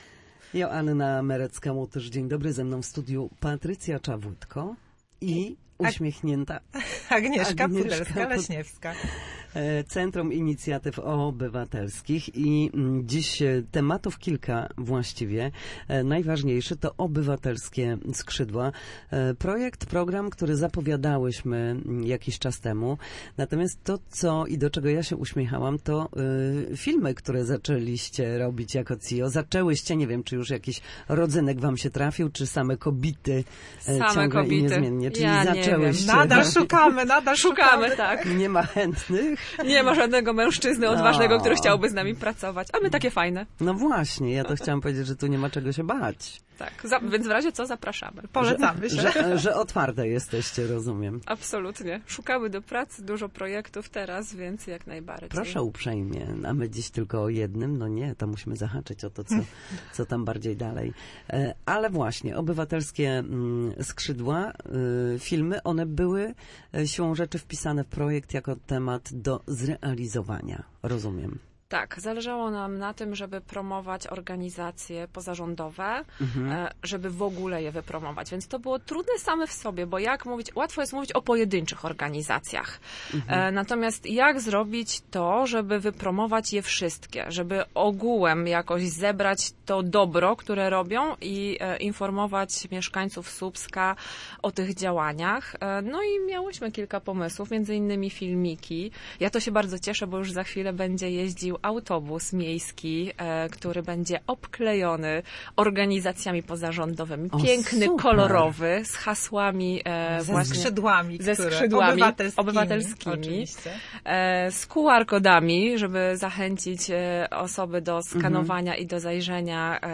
Na naszej antenie mówiły o aktualnych projektach oraz wydarzeniach, które odbędą się jeszcze w grudniu.